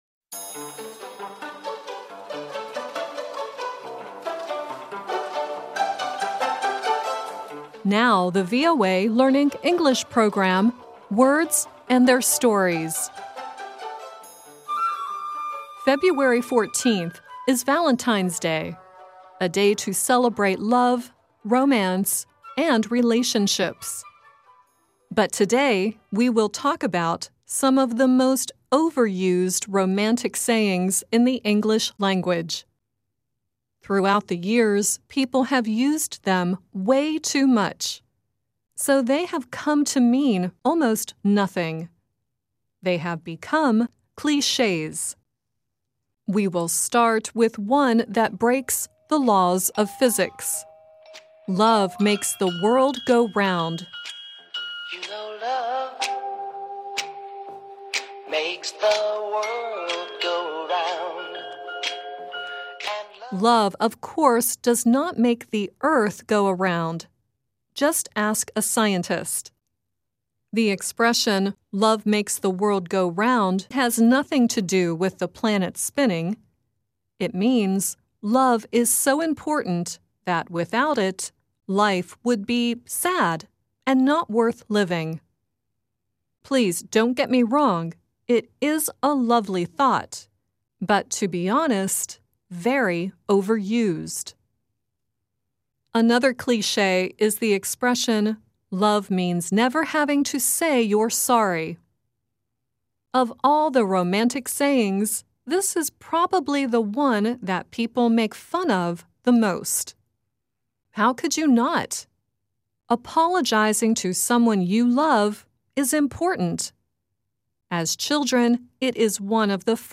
The first song is this program is Deon Jackson singing "Love Makes the World Go ‘Round" and the last one is Nazareth singing "Love Hurts."